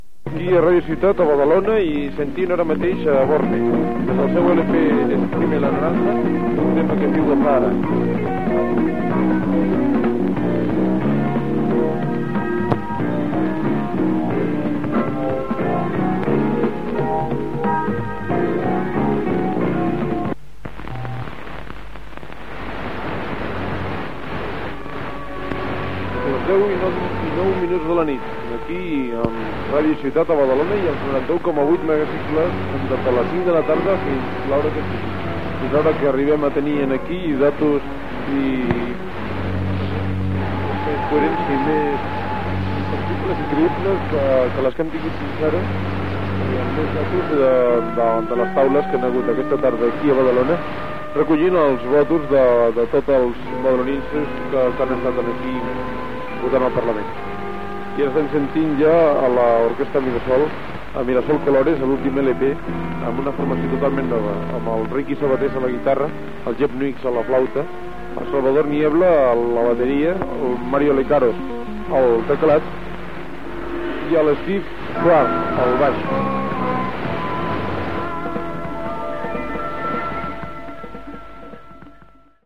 Programació musical i avís de l'especial eleccions al Parlament de Catalunya.
FM